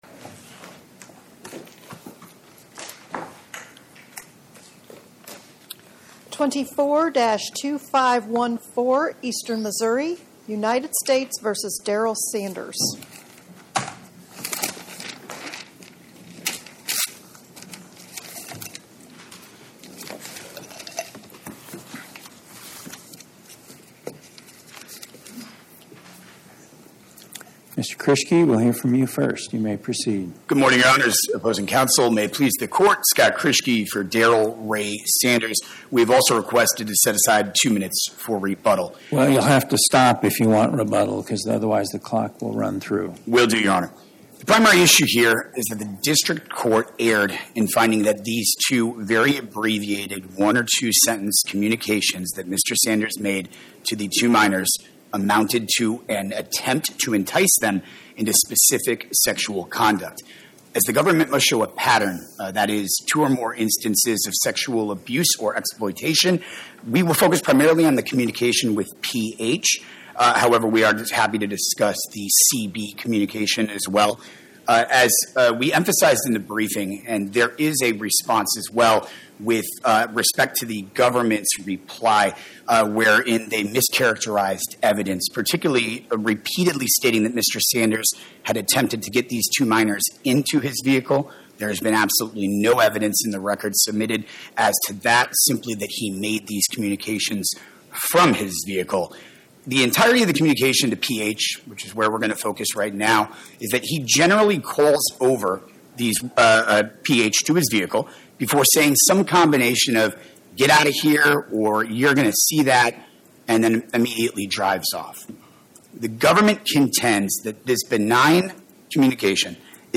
Oral argument argued before the Eighth Circuit U.S. Court of Appeals on or about 09/19/2025